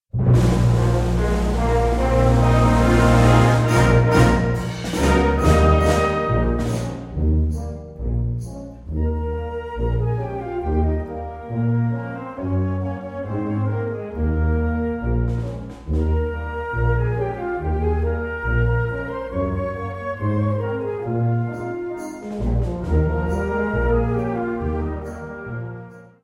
Subcategorie Concertmuziek
Bezetting Ha (harmonieorkest); / (oder); Fa (fanfare)
1 - I. Allegro 1:46